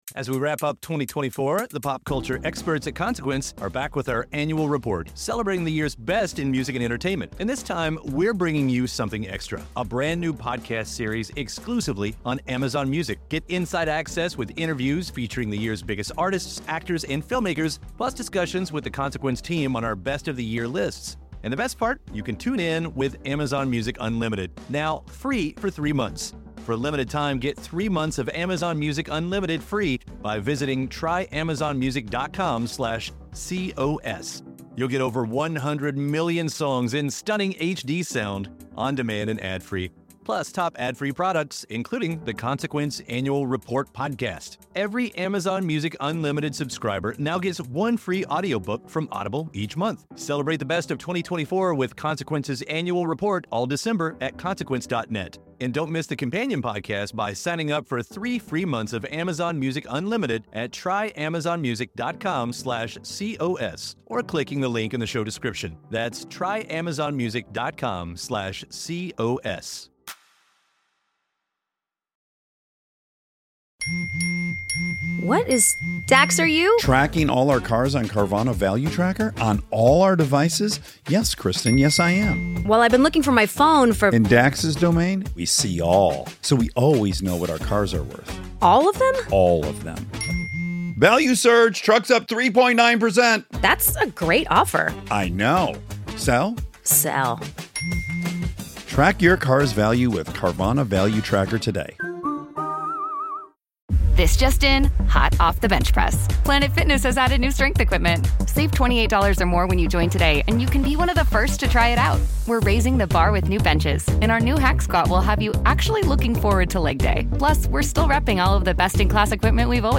Listen to the archival interview now.